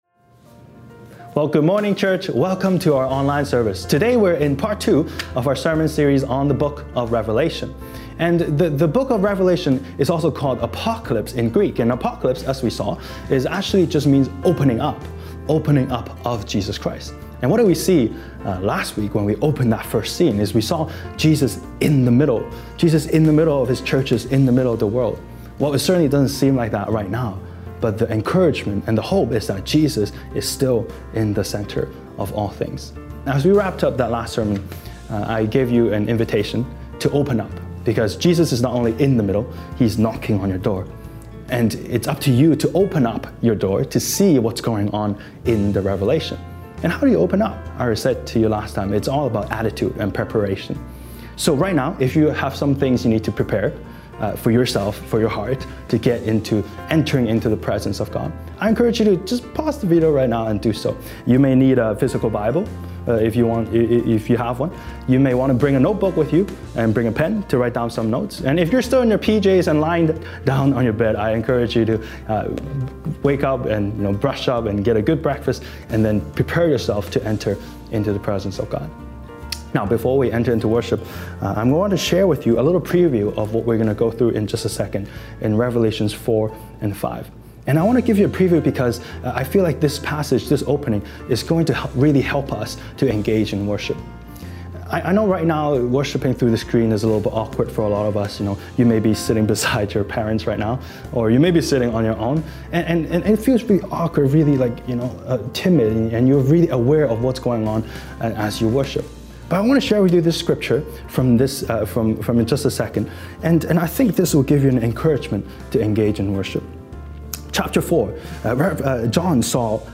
Sermons | Koinonia Evangelical Church (English - NEW duplicate)